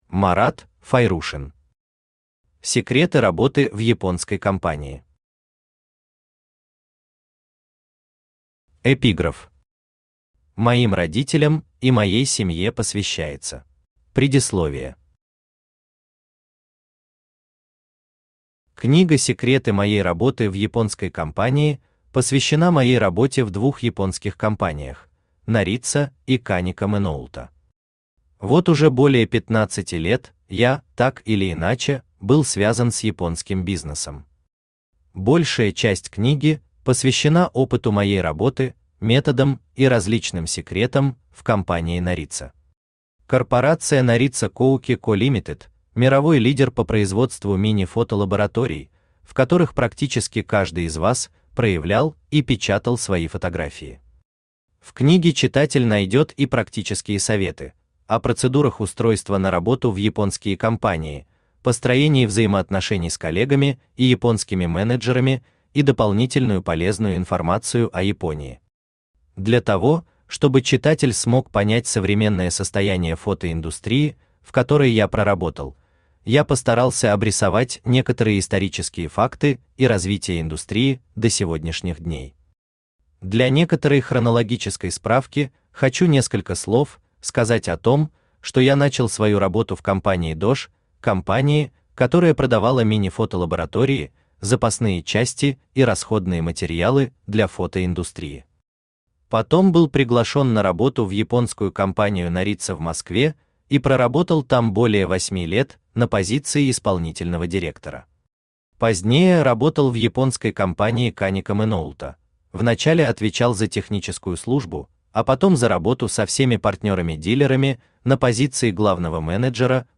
Аудиокнига Секреты работы в японской компании | Библиотека аудиокниг
Aудиокнига Секреты работы в японской компании Автор Марат Глимханович Файрушин Читает аудиокнигу Авточтец ЛитРес.